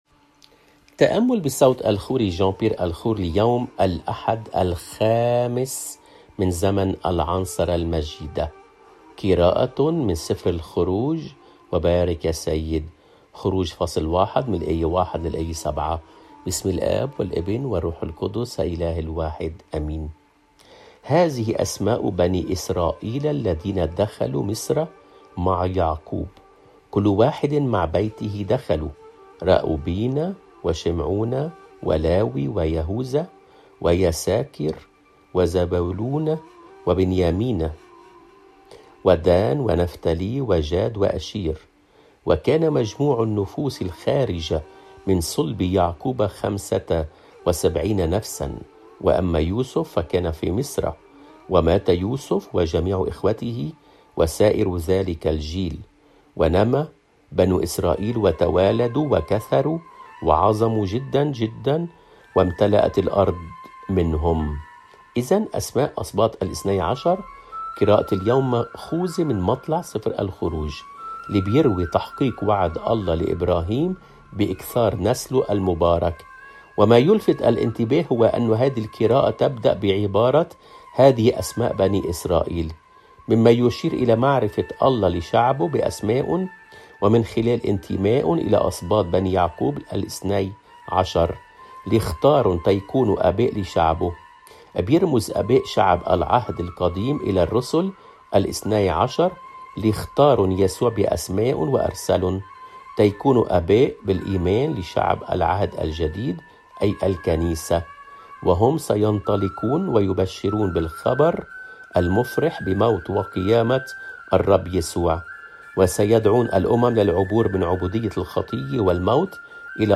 قراءة من العهد القديم